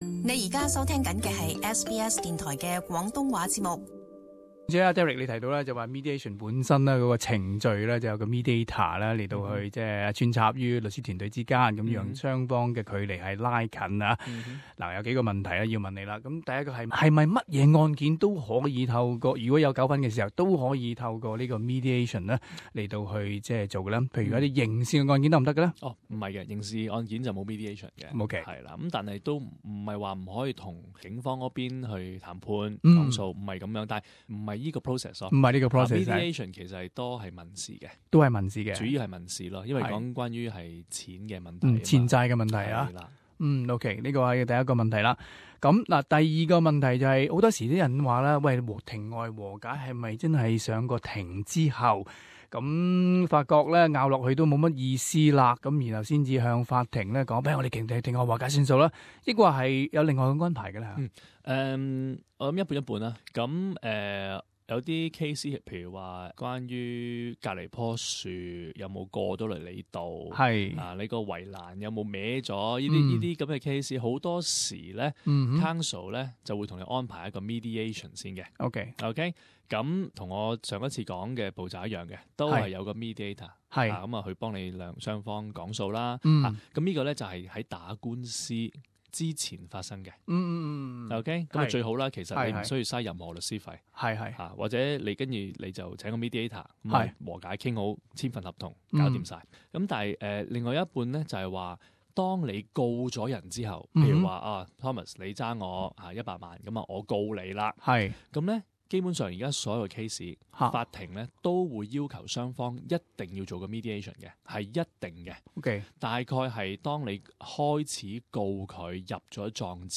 SBS Cantonese